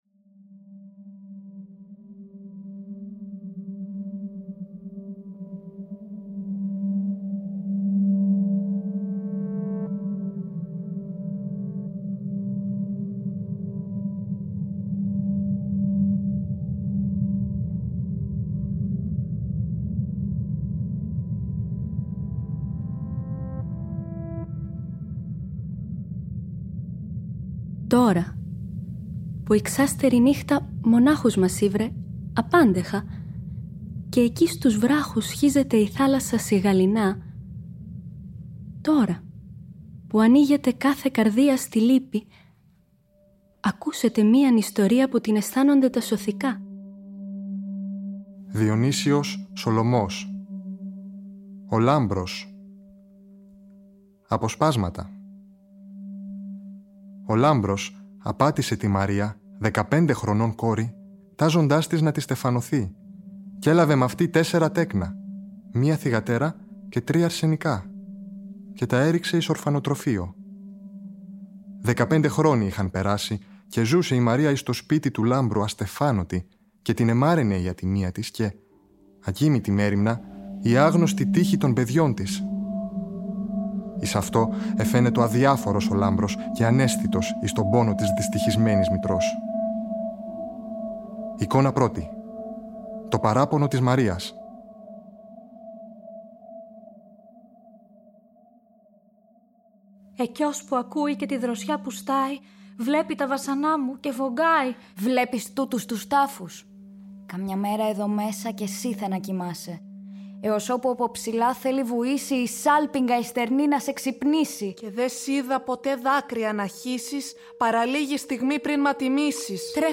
Οι σπουδαστές του Τρίτου Έτους της Δραματικής Σχολής του Ωδείου Αθηνών του παλαιότερου εκπαιδευτικού οργανισμού της χώρας για τη Μουσική και το Θέατρο (1871) συναντήθηκαν στα studio του Τρίτου Προγράμματος και δημιούργησαν το πρώτο ραδιοφωνικό θεατρικό έργο.